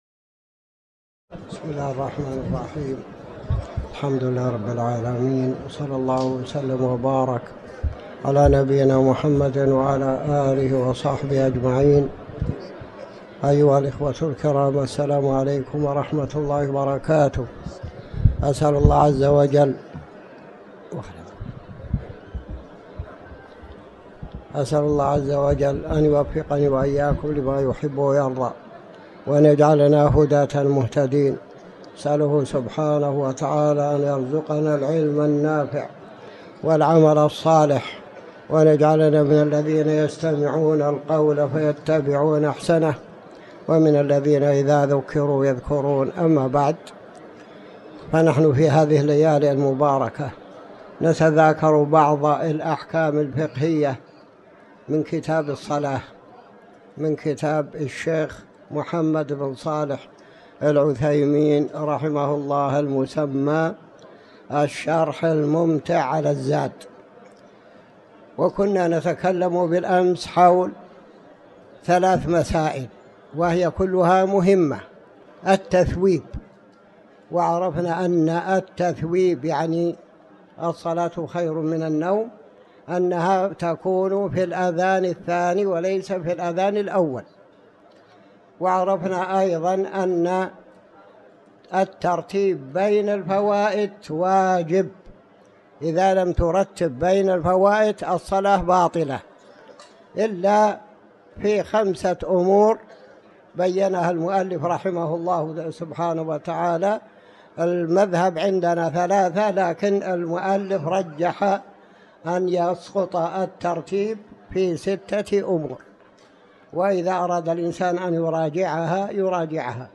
تاريخ النشر ٥ جمادى الآخرة ١٤٤٠ هـ المكان: المسجد الحرام الشيخ